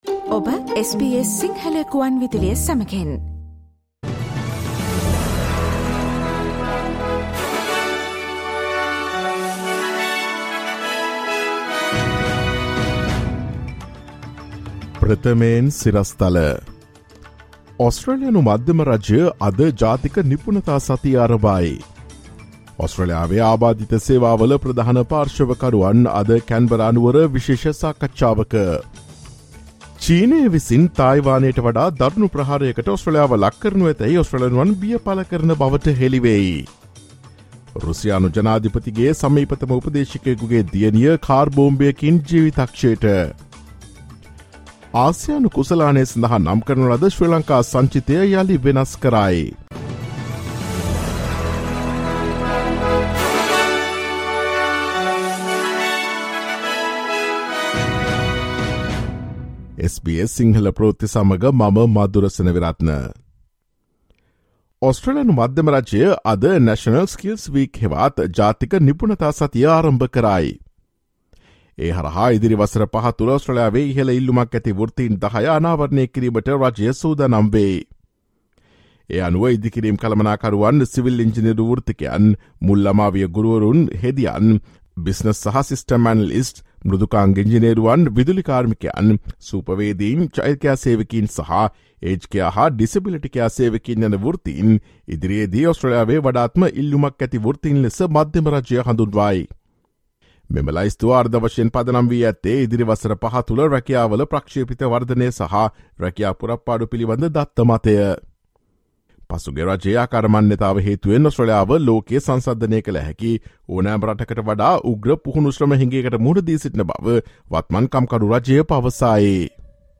ඔස්ට්‍රේලියාවේ නවතම පුවත් මෙන්ම විදෙස් පුවත් සහ ක්‍රීඩා පුවත් රැගත් SBS සිංහල සේවයේ 2022 අගෝස්තු 22 වන දා සඳුදා වැඩසටහනේ ප්‍රවෘත්ති ප්‍රකාශයට සවන් දෙන්න.